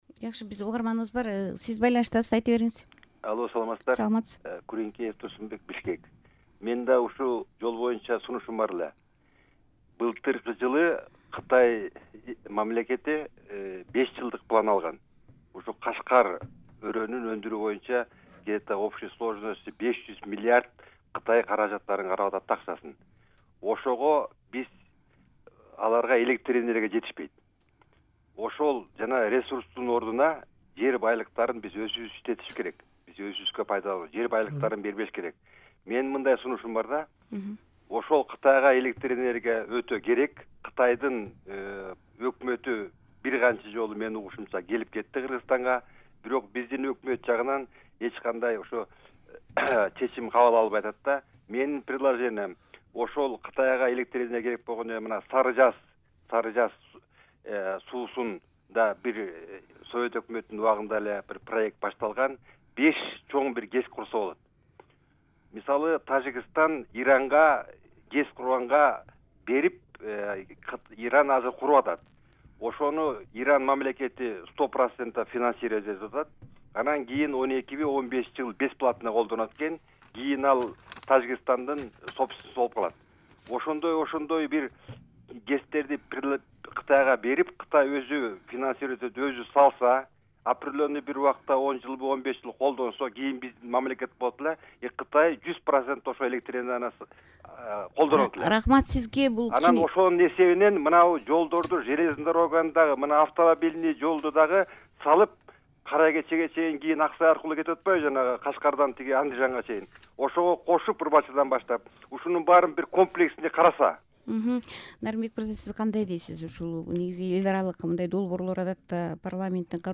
Жол куруу тууралуу талкуу (2-бөлүк)